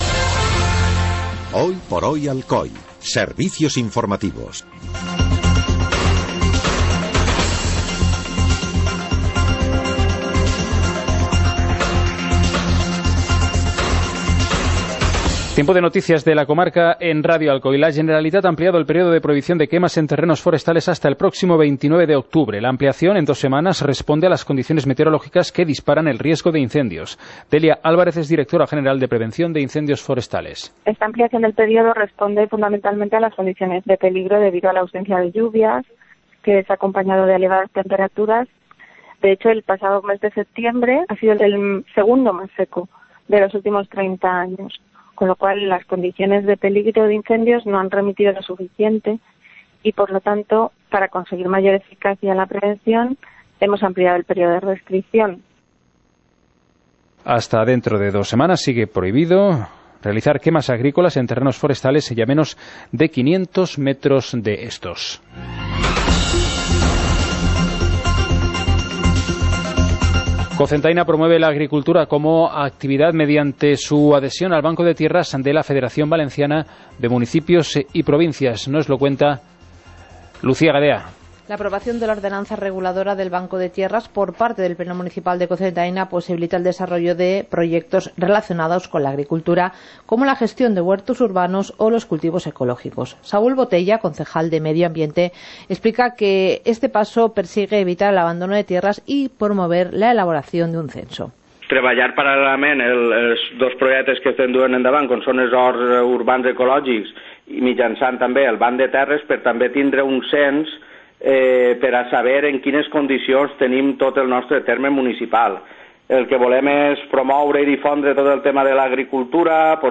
Informativo comarcal - lunes, 16 de octubre de 2017